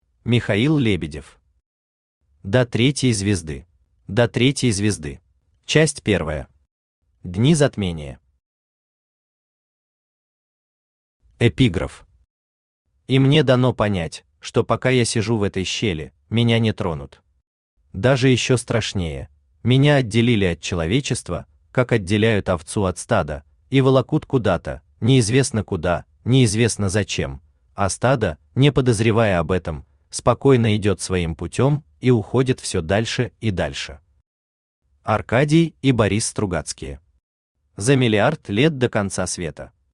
Аудиокнига До третьей звезды | Библиотека аудиокниг
Aудиокнига До третьей звезды Автор Михаил Лебедев Читает аудиокнигу Авточтец ЛитРес.